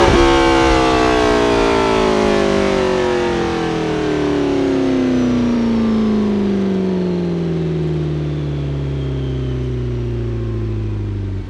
rr3-assets/files/.depot/audio/Vehicles/ttv8_01/ttv8_01_Decel.wav